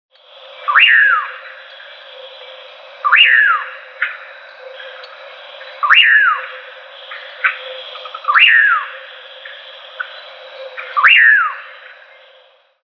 Nyctidromus albicollis (common pauraque)
A Common Pauraque (Nyctidromus albicollis) calls in the early evening at the "El Pizote" in Puerto Viejo de Talamanca (Costa Rica), 6/22/99, 5:40 PM.
He apparently never strayed far from that spot, as these recordings were made 12 hours later only a few meters from where I had spotted him.